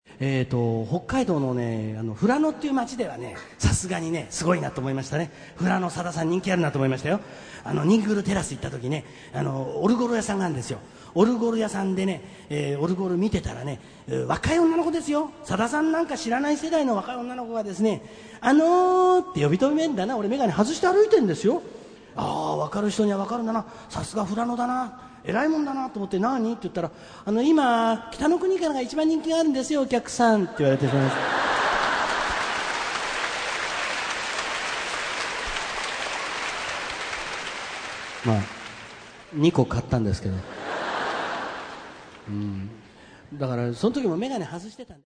ライブアルバム
2005年9月6・7日　日本武道館にて収録